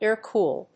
アクセントáir‐còol